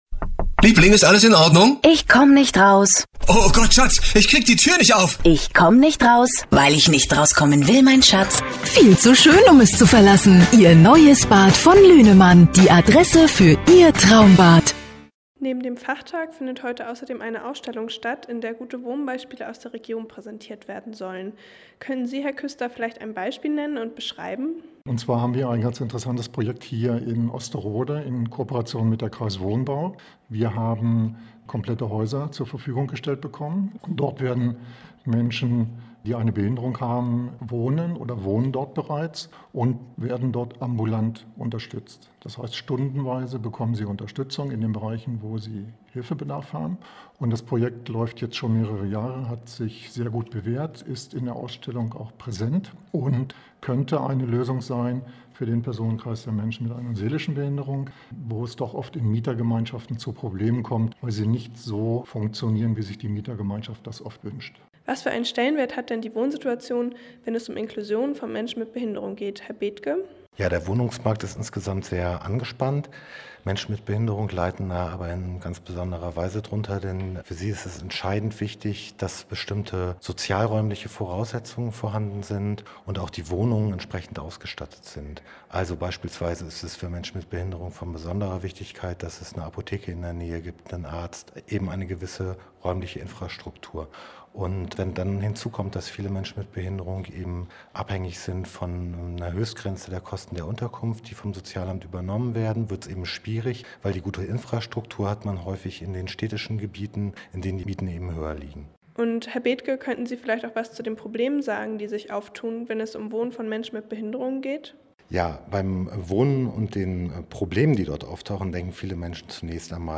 Gespräch über Inklusion und Wohnen